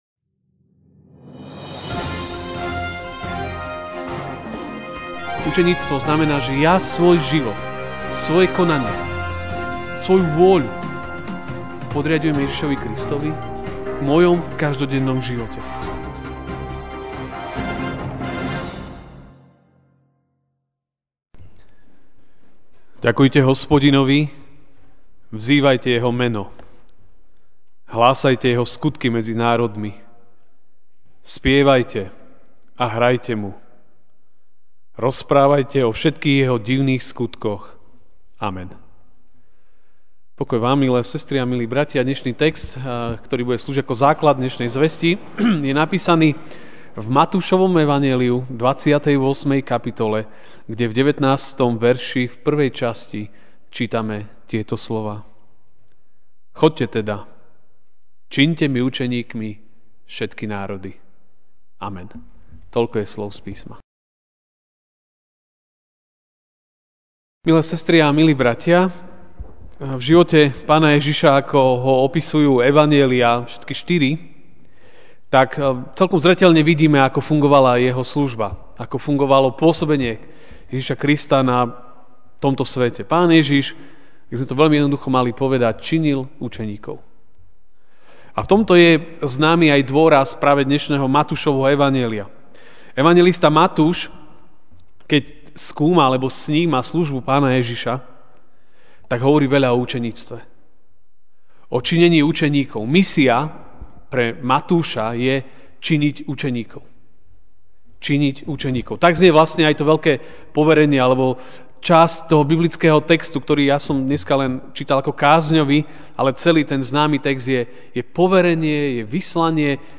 MP3 SUBSCRIBE on iTunes(Podcast) Notes Sermons in this Series Ranná kázeň: Misia: činenie učeníkov!